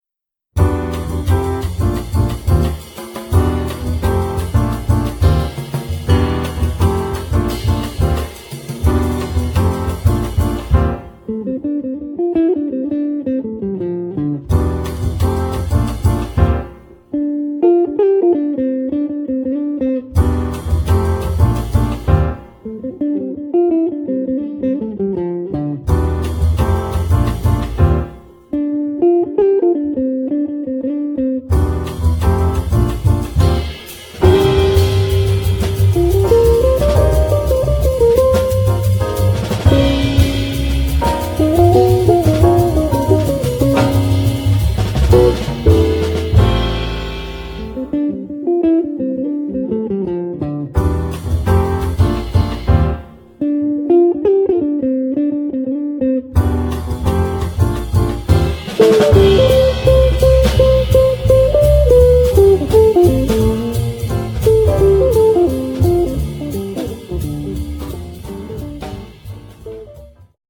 guitar
piano
bass
drums